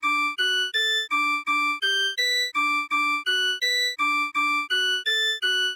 Only diatonic pitches in D-major (the key signature is missing..) — again the contour follows cycle pattern. (the first three pitches are distinct)